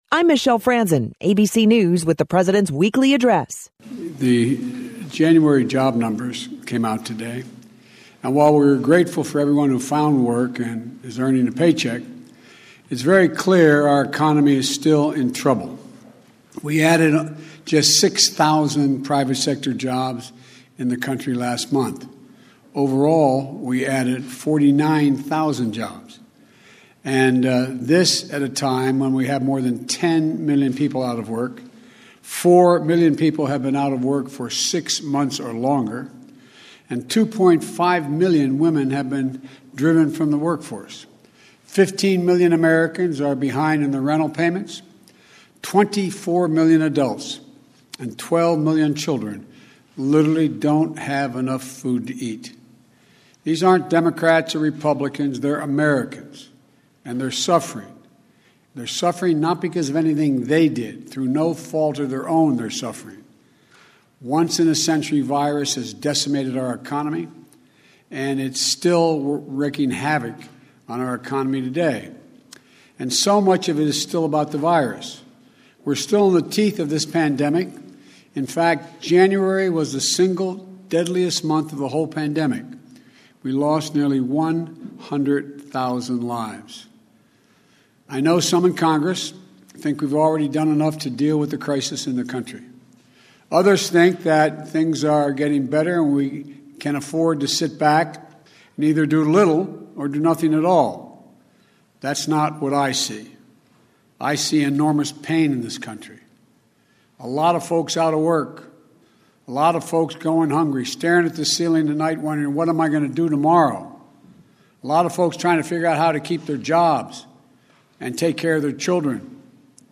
President Joe Biden spoke on the state of the economy and the need for the American Rescue Plan.